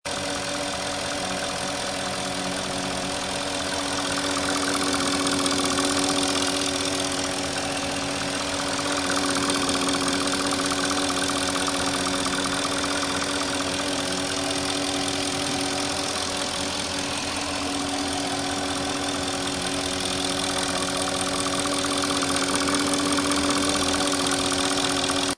audio/mpegRumore metallico cayenne s 955
rumore 955 s.mp3 (263.2 k)
Direi che quel rumore di punterie non è anomalo.